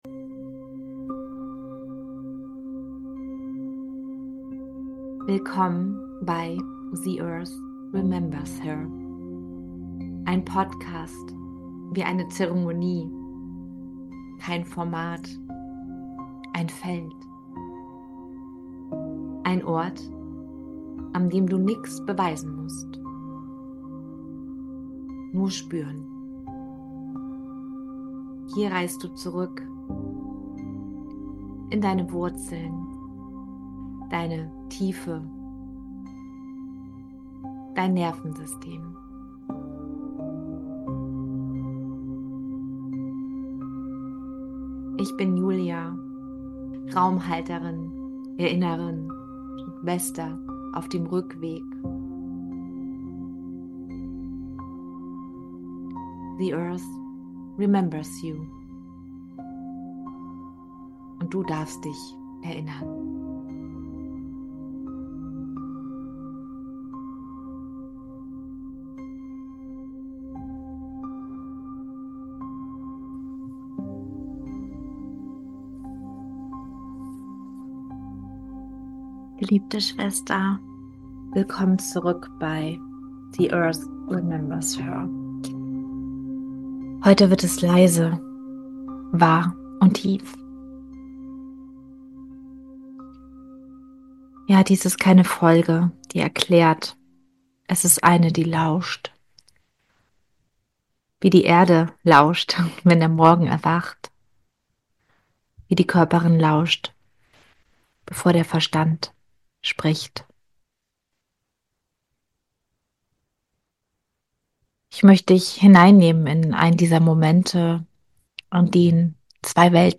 Eine geführte Reise – Wurzeln unten, Weite oben, Atem als Brücke